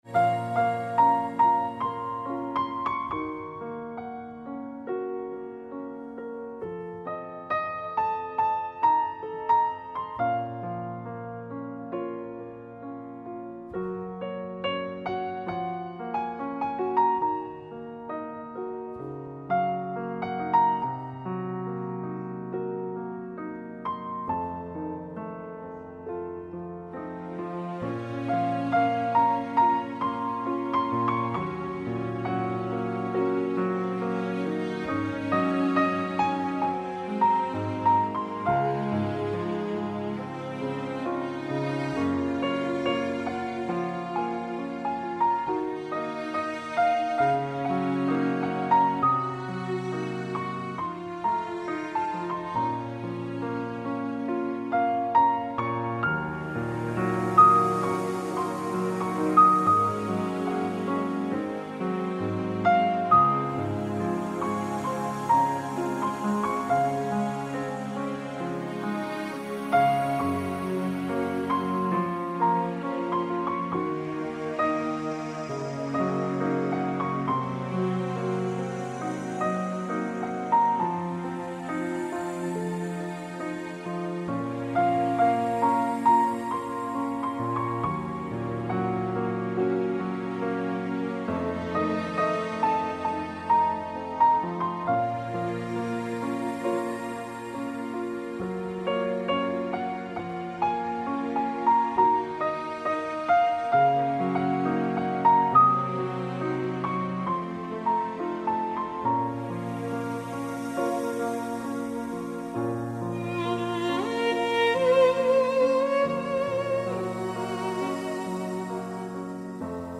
Pianino,skripka.mp3